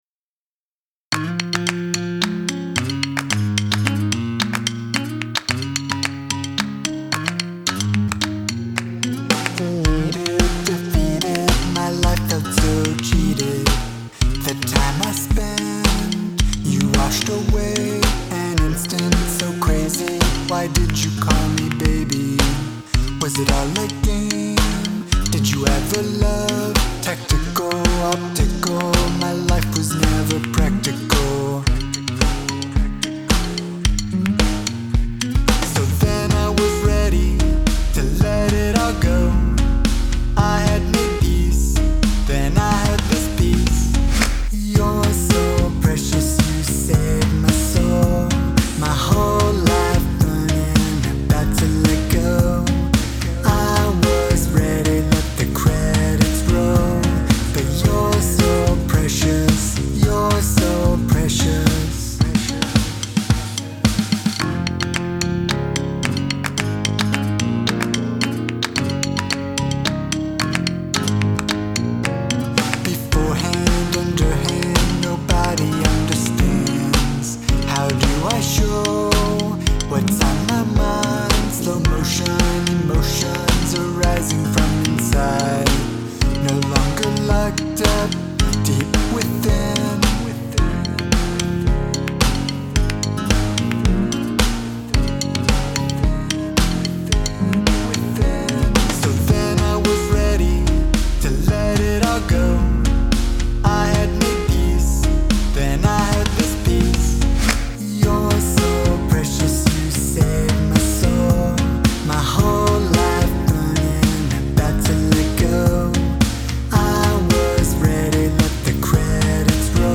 I have attached my newest mix of a song. To me, it sounds heavier and less smooth than what I hear on the radio in terms of production quality.